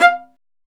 Index of /90_sSampleCDs/Roland - String Master Series/STR_Viola Solo/STR_Vla Marcato